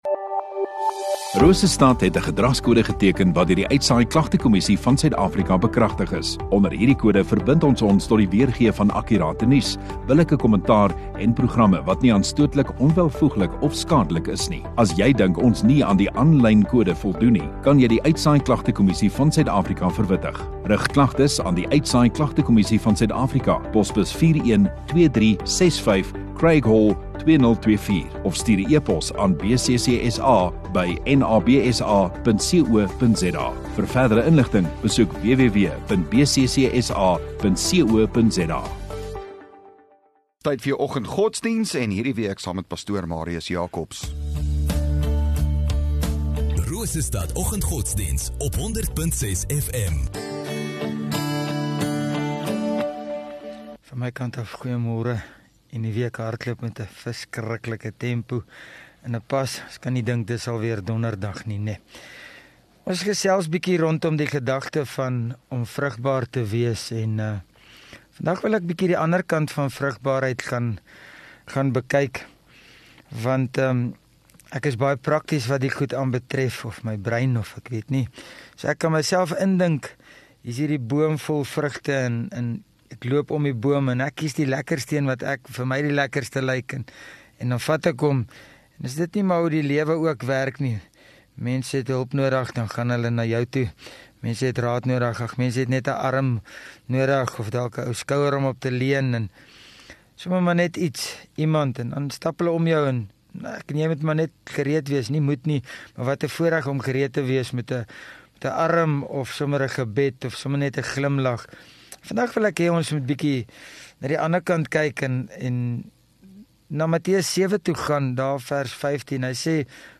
19 Feb Donderdag Oggenddiens